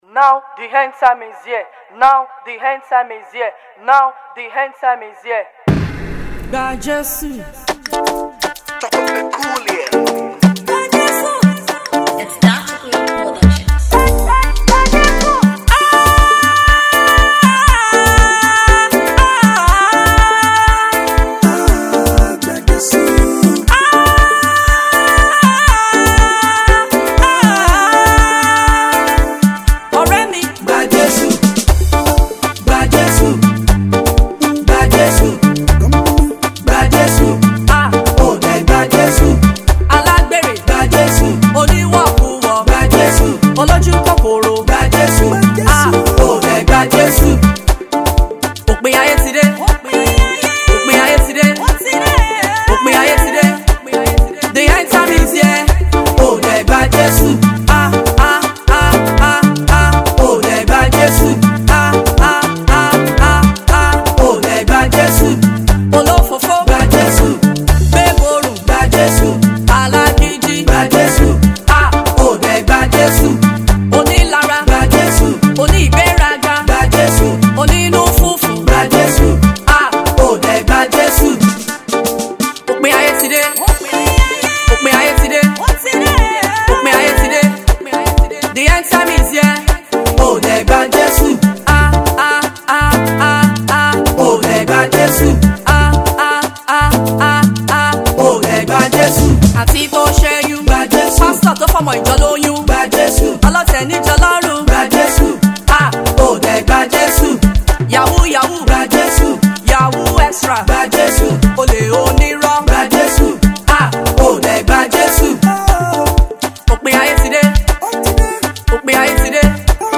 gospel jamz song